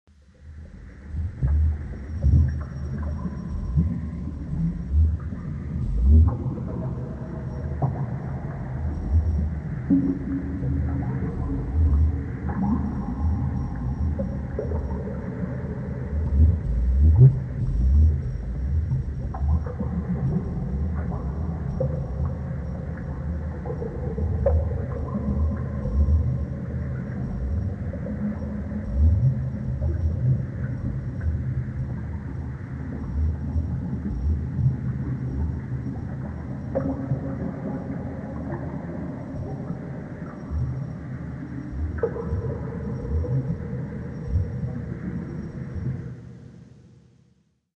WATER-UNDERWATER FX SCUBA: Bottom of the ocean, deep underwater.